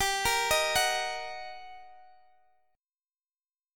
Listen to GmM7#5 strummed